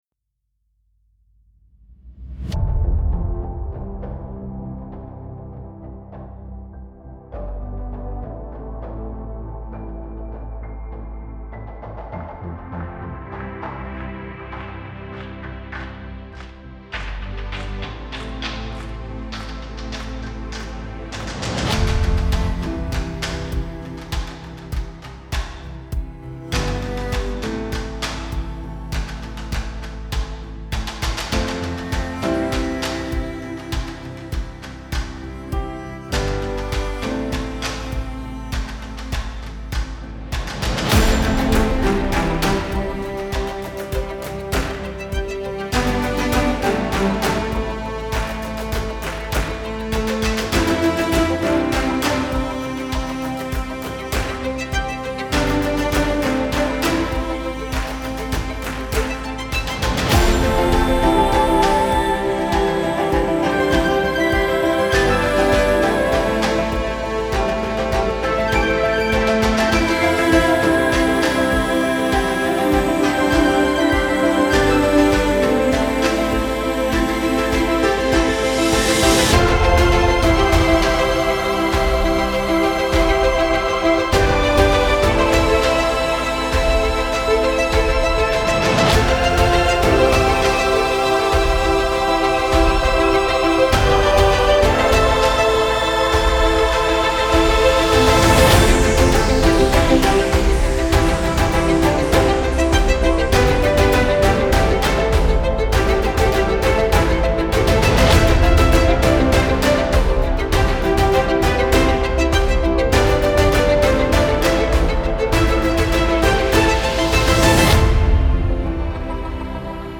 موسیقی کنار تو
سبک اپیک , الهام‌بخش , حماسی , موسیقی بی کلام